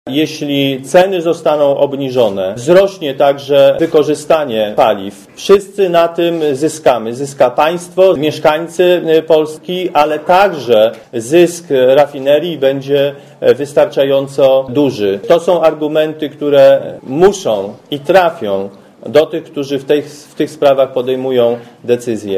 * Mówi Kazimierz Marcinkiewicz*